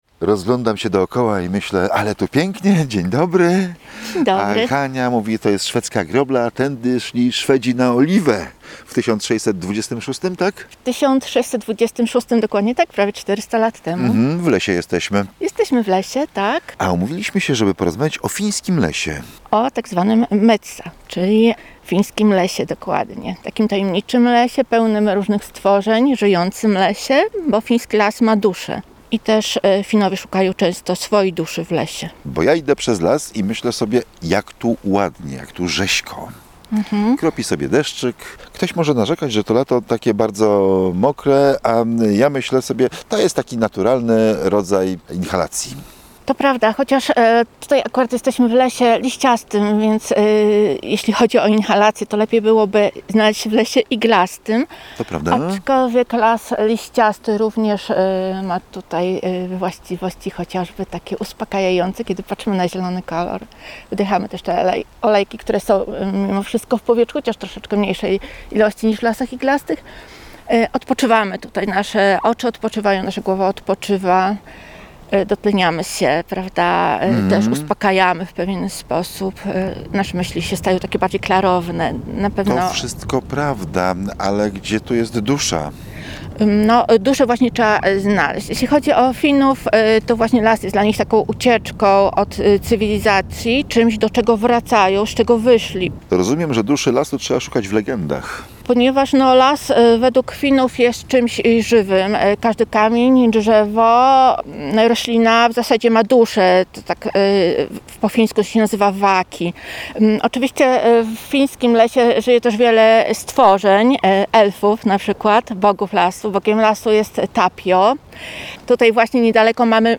Na spacerze lasami oliwskimi opowiada